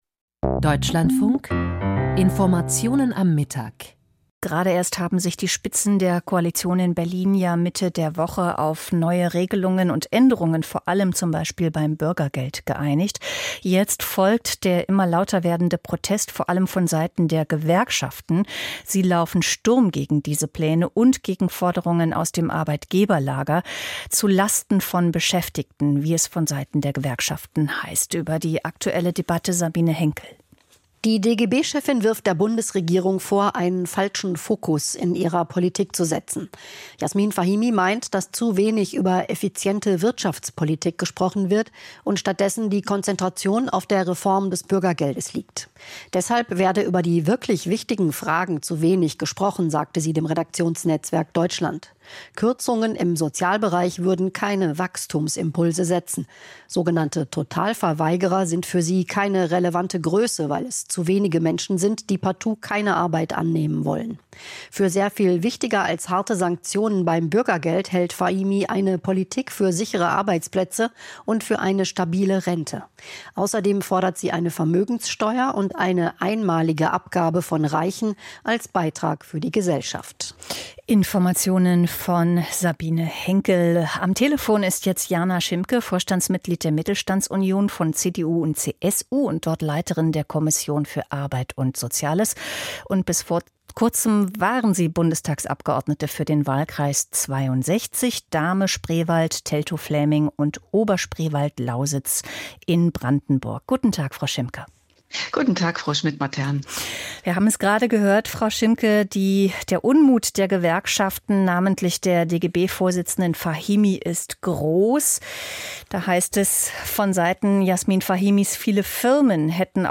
Sozialstaatdebatte - Interview mit Jana Schimke, Mittelstandsvereinigung CDU/CSU